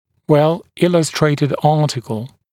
[ˌwel’ɪləstreɪtɪd ‘ɑːtɪkl][ˌуэл’илэстрэйтид ‘а:тикл]хорошо иллюстрированная статья